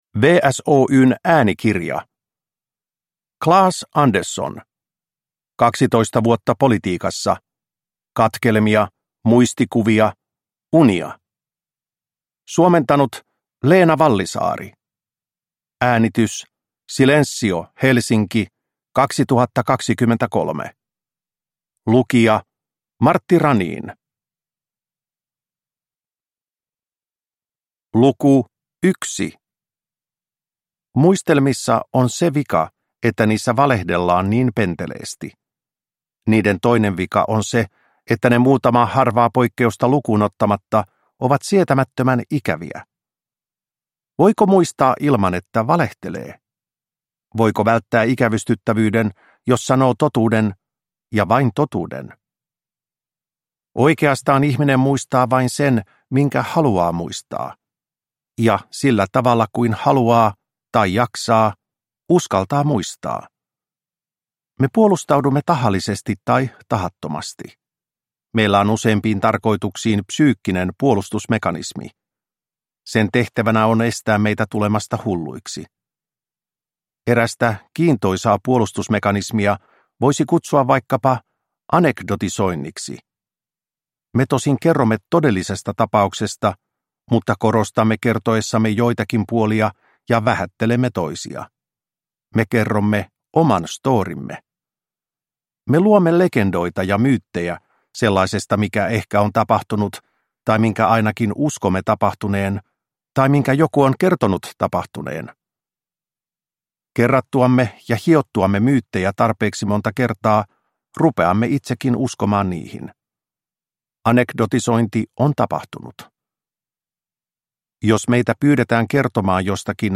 Kaksitoista vuotta politiikassa – Ljudbok